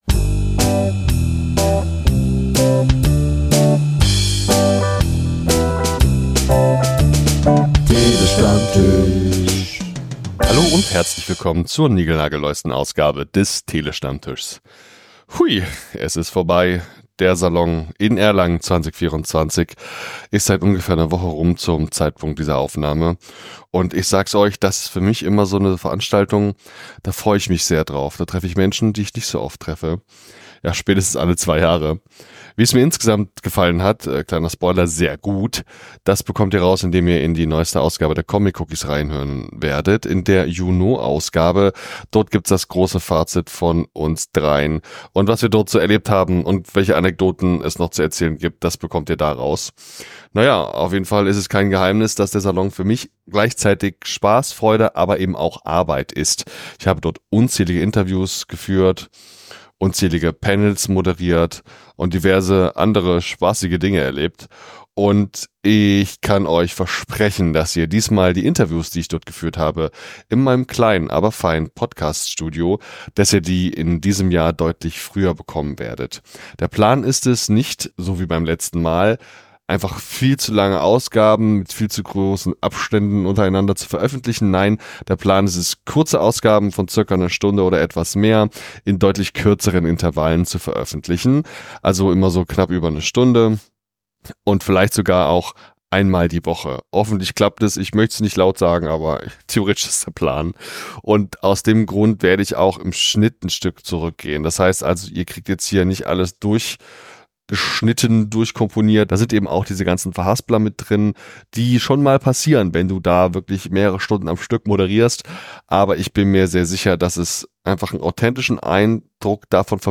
Ich habe vor Ort sehr viel gearbeitet und viele Stunden lang Interviews geführt und aufgezeichnet.